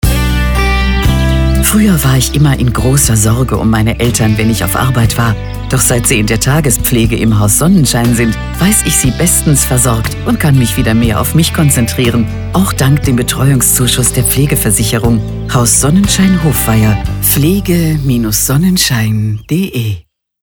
4. Authentische Alltagsstimme
Klingen bewusst „echt“ und nahbar – ideal für lokale Werbung und persönliche Botschaften.